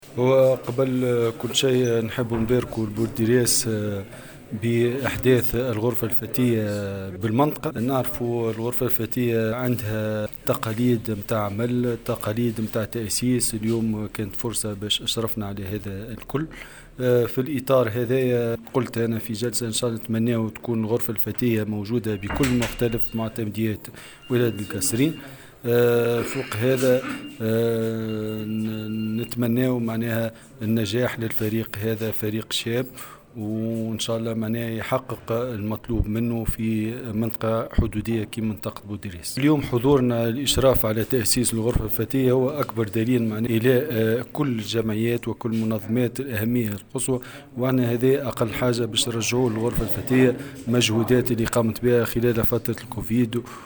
والي القصرين محمد سمشة يتحدث عن الغرفة المحدثة: